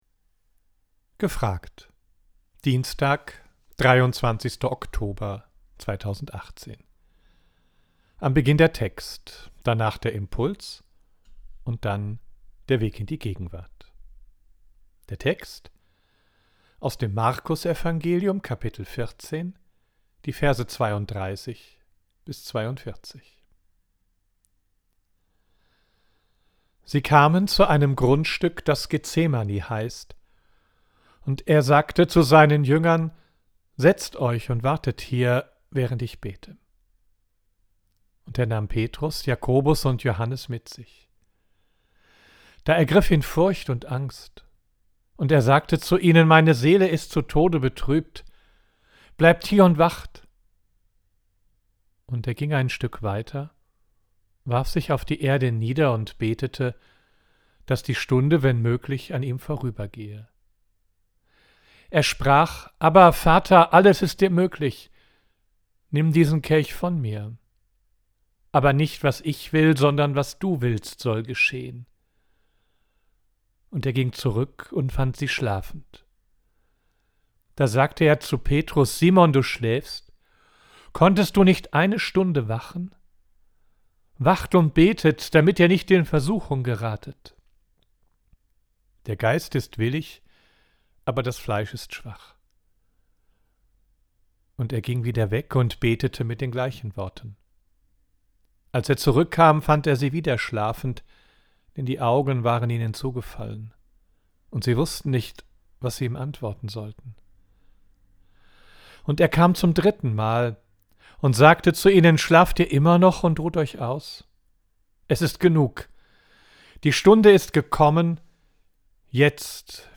Audio: Text und Impuls